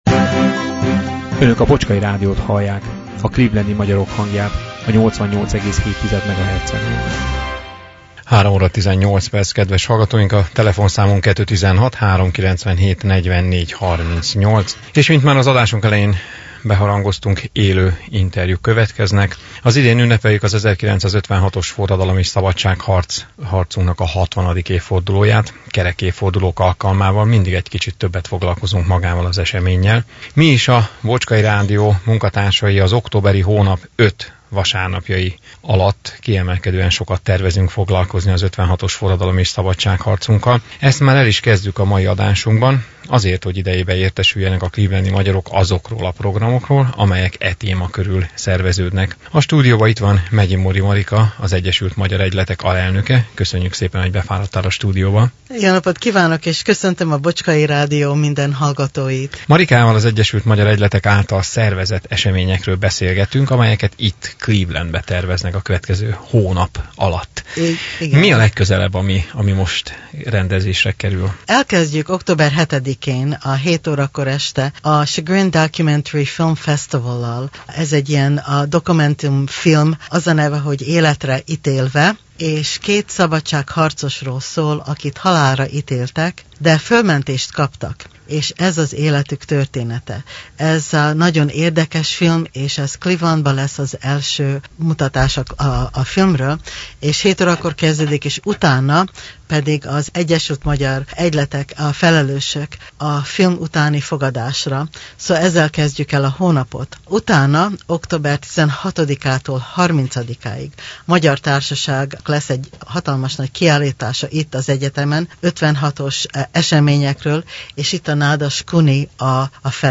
élő interjút